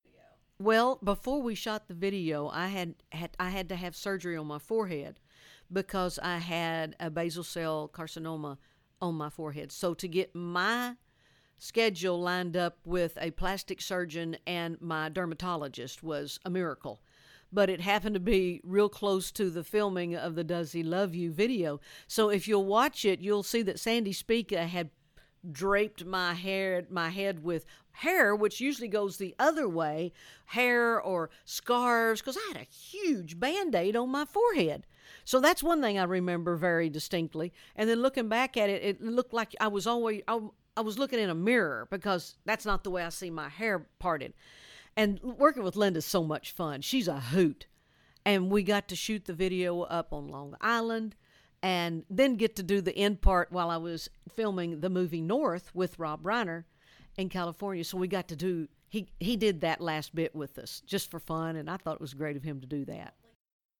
Audio / Reba McEntire recalls some of the more memorable moments of the original “Does He Love You” video.